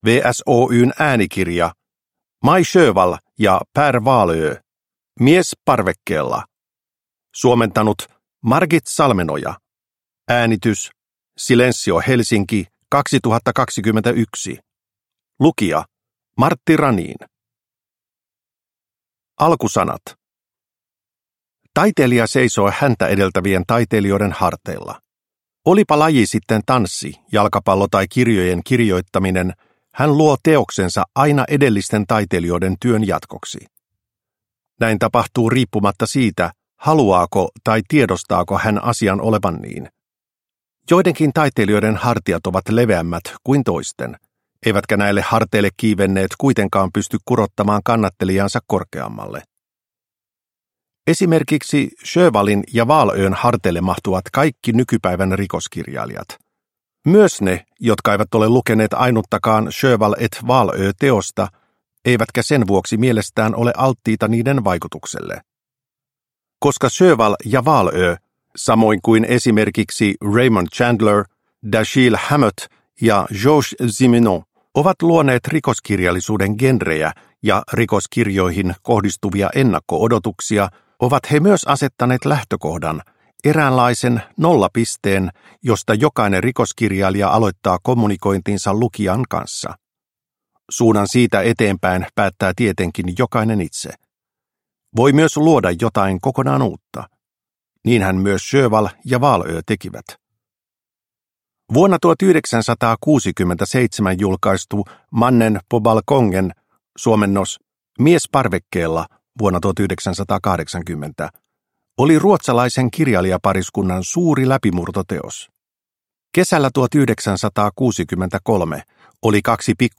Mies parvekkeella – Ljudbok – Laddas ner